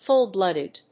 fúll‐blòoded
音節fùll-blóoded